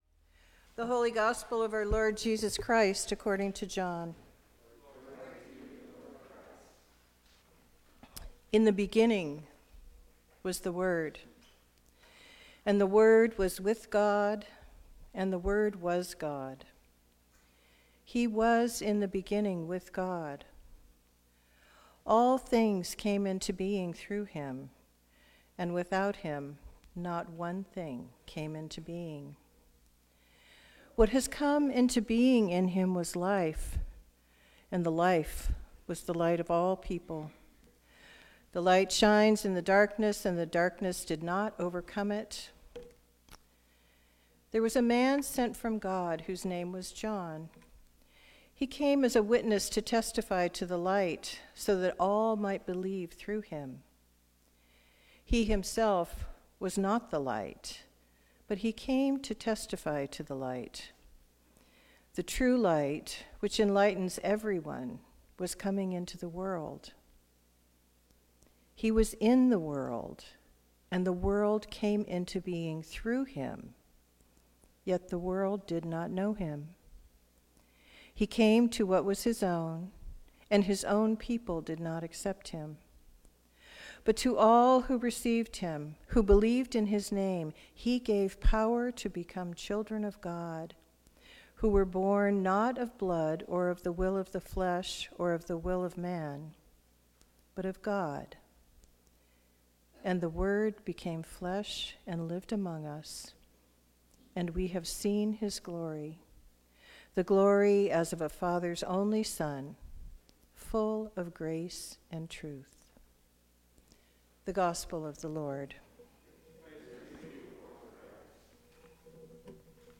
Christmas Day Service